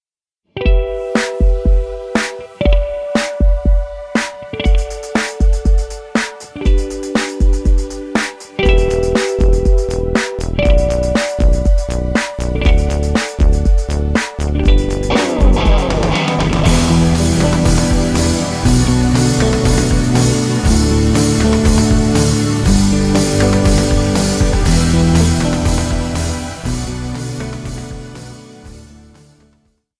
vocals, synthesizers, guitar, drum programming